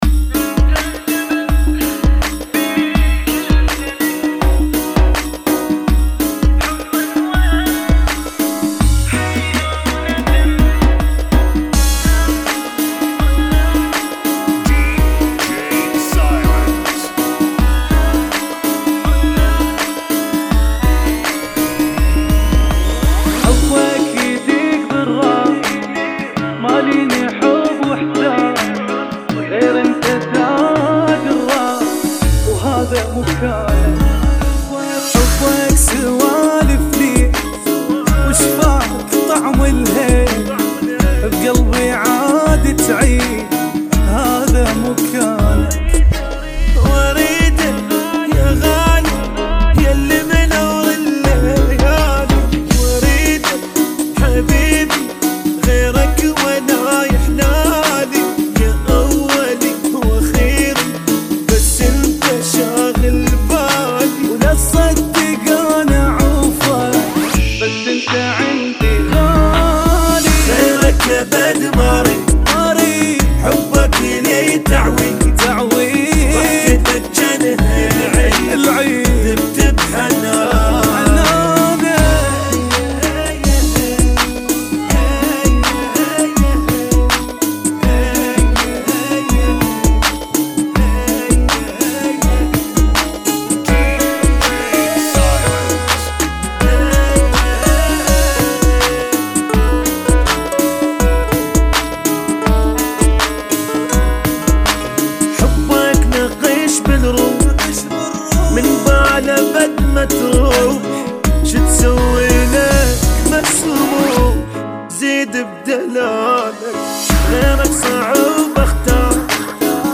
[ 82 Bpm ]